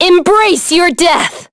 Lucikiel_L-Vox_Skill2.wav